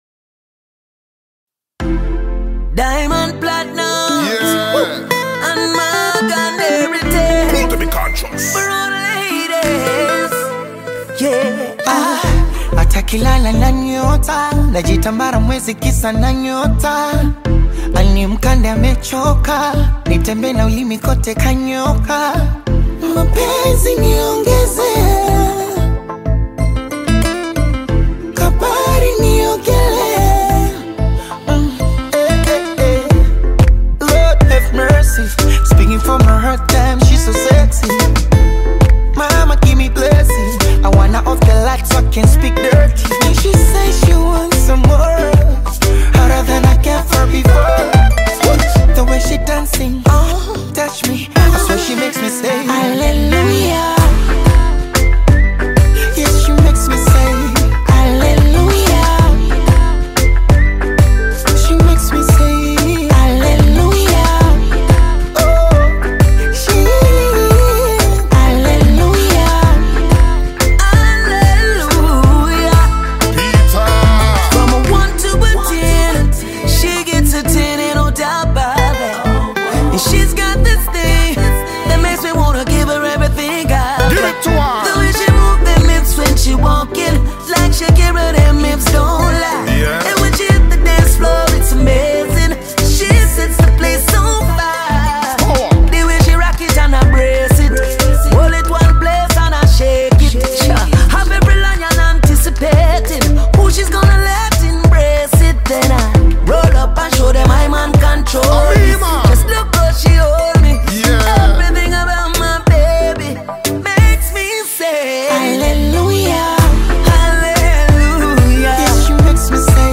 Afro-Pop single
reggae band
blending Afro-beats with island-tinged rhythms